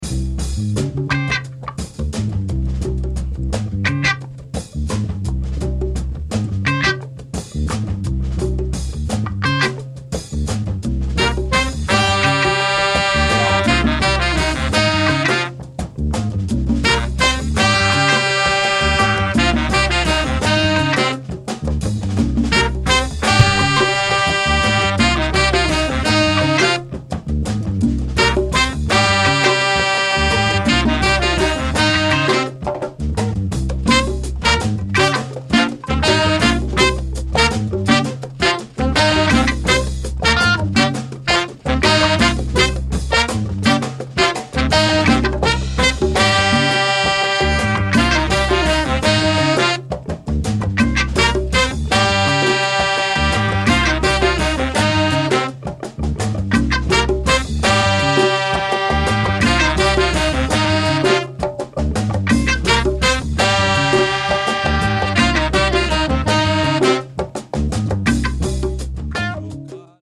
Killer deep spiritual Jazz compilation.